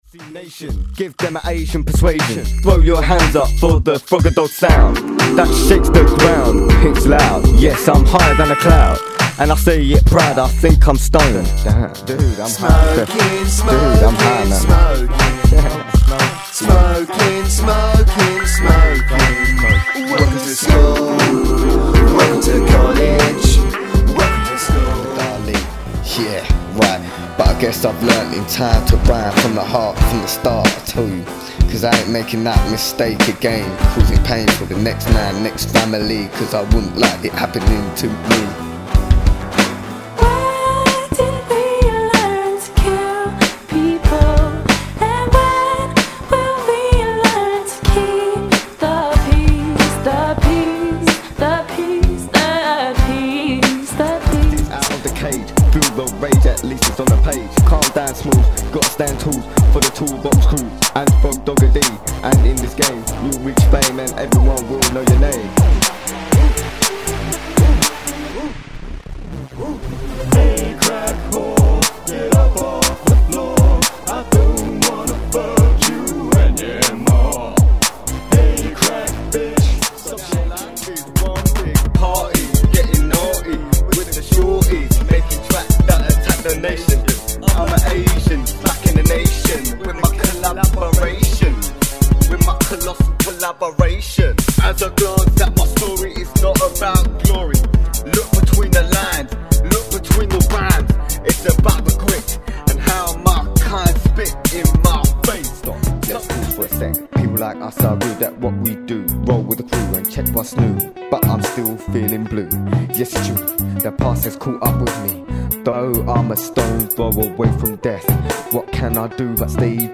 15 track debut studio album from the UK underground rapper